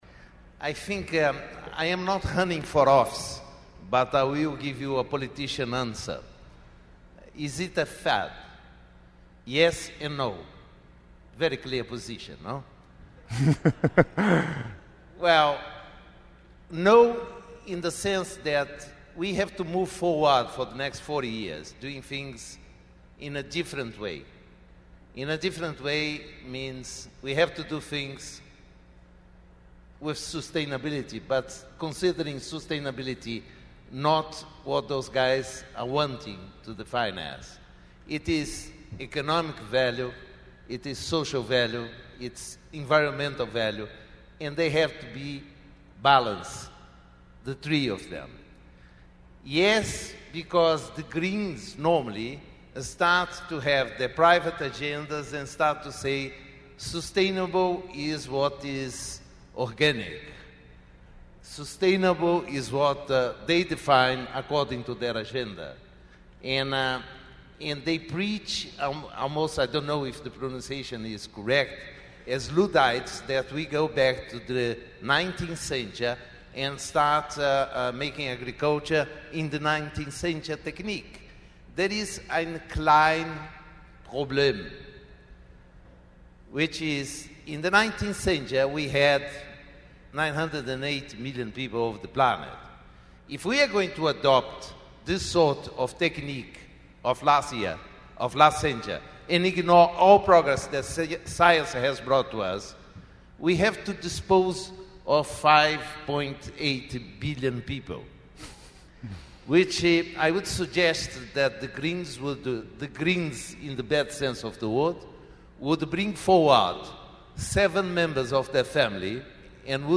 Great Debate at Alltech Symposium